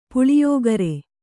♪ puḷiyōgare